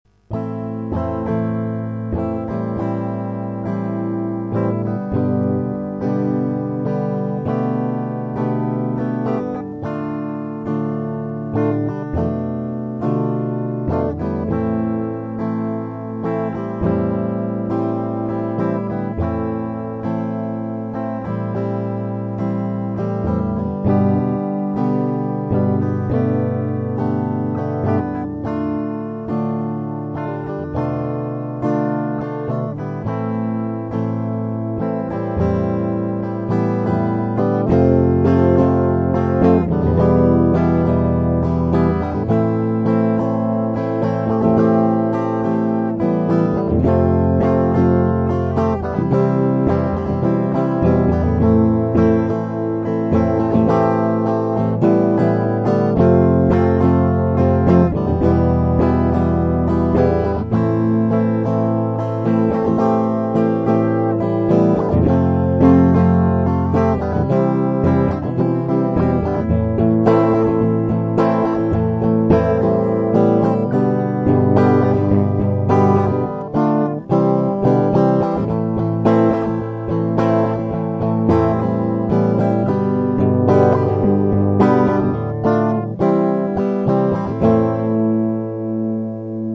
mp3 - куплет, припев